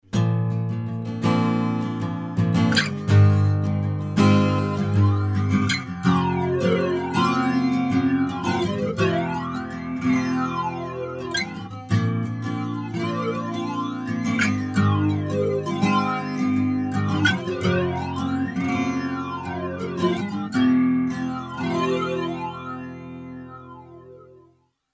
Digital delay with pitchshifting engine plus special tridimensional acoustic Roland RSS system.
modulation guitar LFO
modulation.mp3